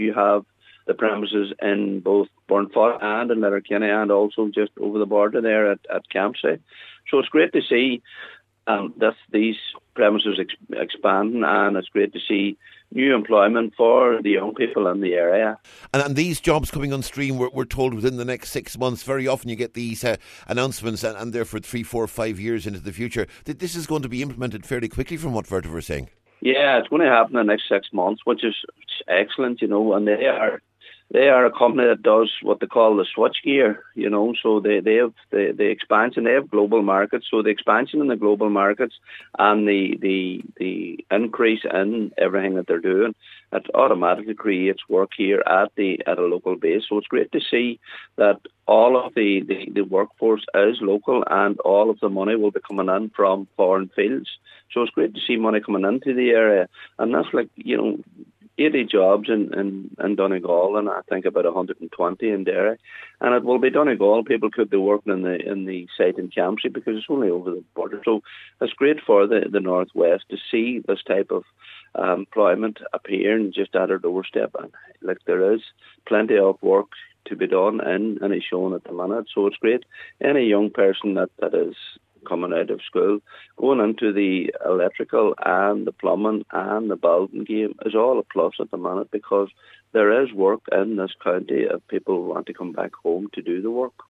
Cllr Paul Canning says it’s a positive time for those seeking employment in Donegal……………..